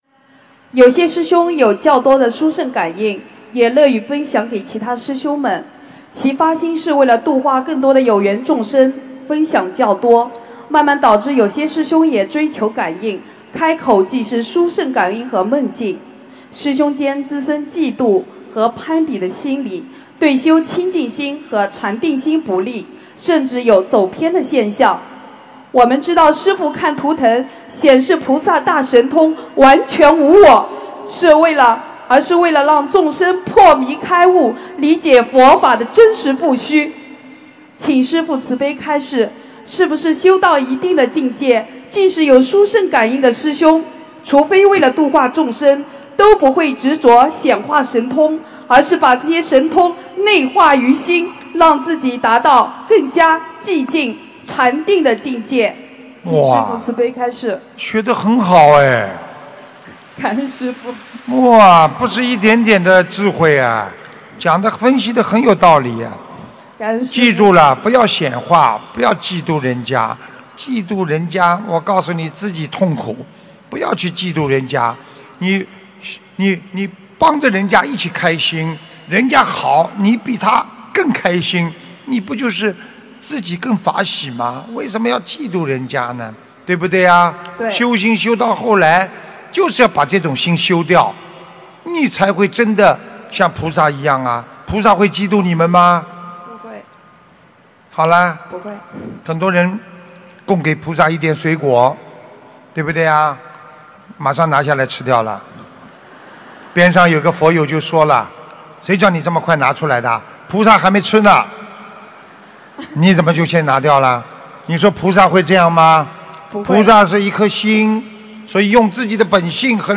自己或别人有殊胜感应或梦境时，应以何种心态对待——弟子提问 师父回答--2017年马来西亚吉隆坡弘法解答会（2）博客 2018-04-03 ...